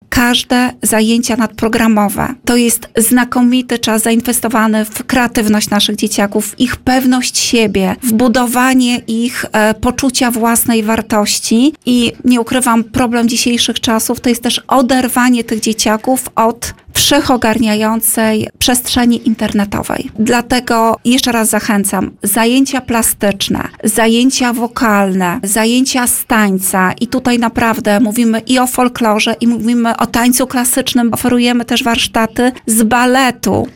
– To szansa, by odkryć w sobie pasję, rozwijać talenty i spędzić wolny czas twórczo – zaprasza Anna Gawrych, wicestarosta łomżyński.